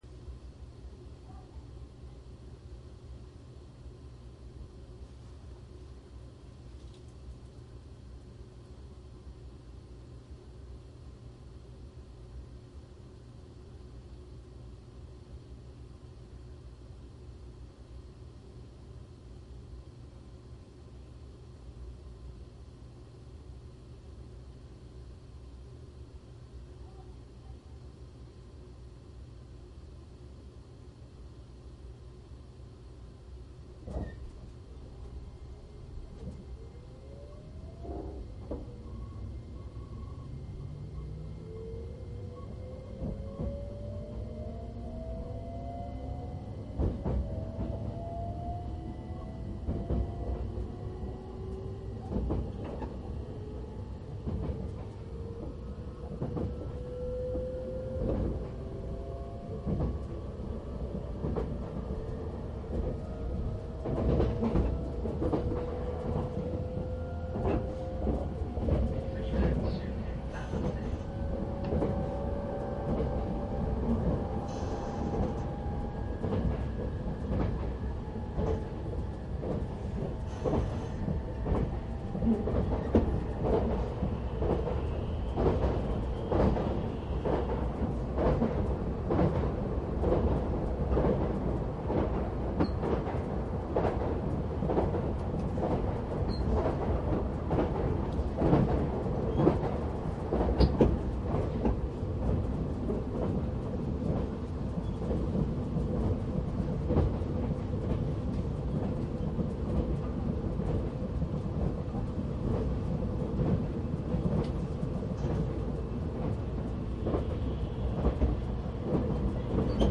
商品説明JR篠ノ井線『快速』E257  鉄道走行音 ＣＤ ♪
内容はJR篠ノ井線『快速』E257  走行音 録音 ＣＤです。
■【快速】松本→長野 モハE257-3
マスター音源はデジタル44.1kHz16ビット（マイクＥＣＭ959）で、これを編集ソフトでＣＤに焼いたものです。